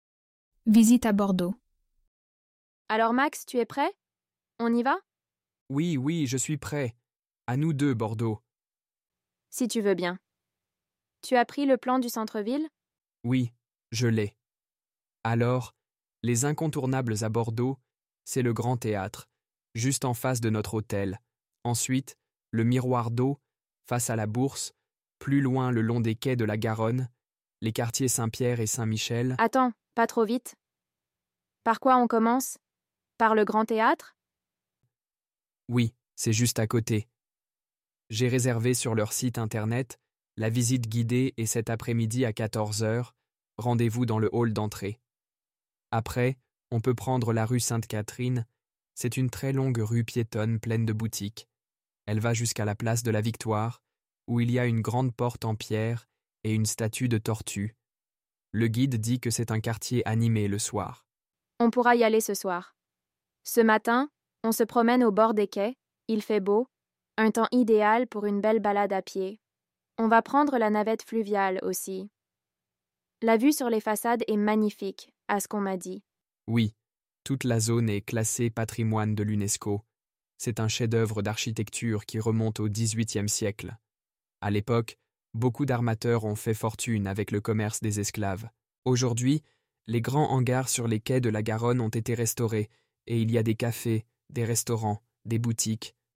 Visite à Bordeaux – Dialogue en français niveau B2